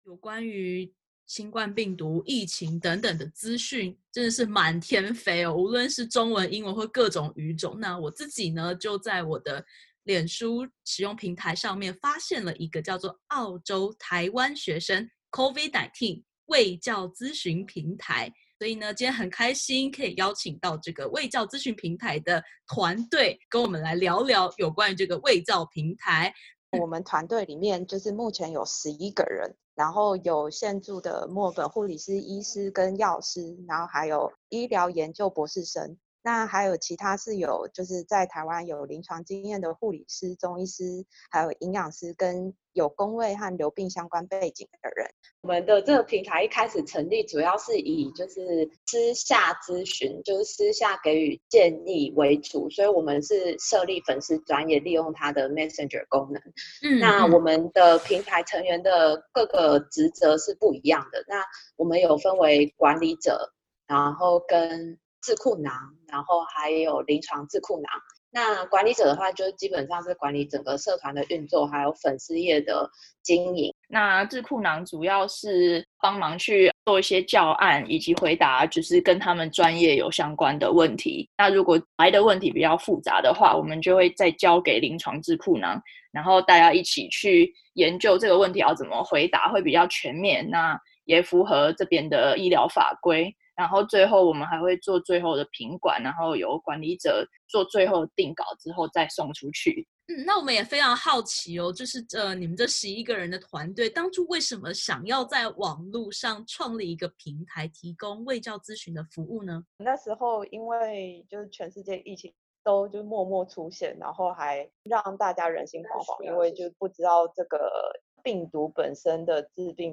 一支具备医护和公卫背景的志愿性团队，在网路上成立中文卫教平台，力求提供最精准且浅显易懂的资讯。点击图片收听完整采访。